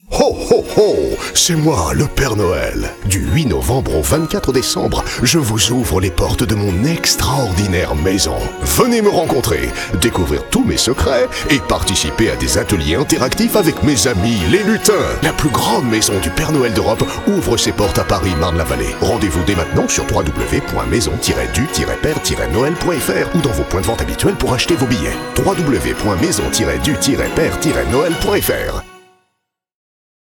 Voix du Père Noël, enthousiaste.
Spot radio pour « La maison du Père Noël ».
Voix off d’un Père Noël joyeux, enthousiaste et assez dynamique.